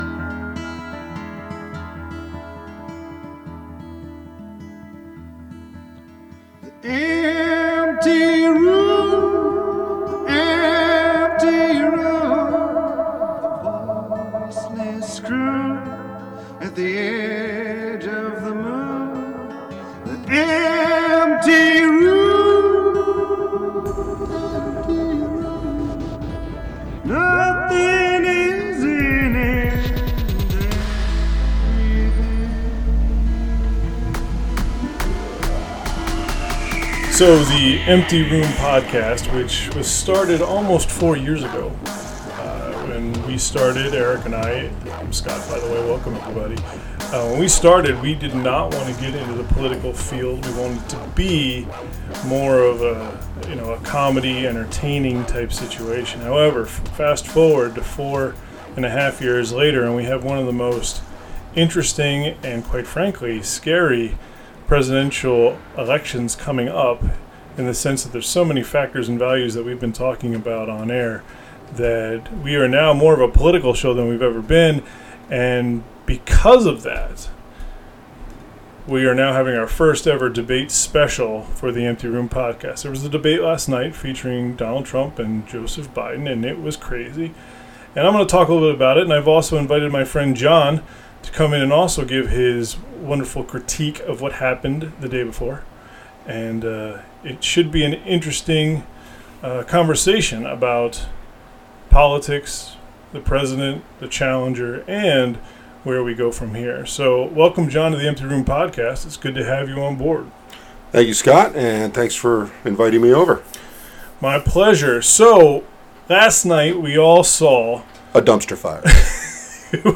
commentary – The Empty Room Podcast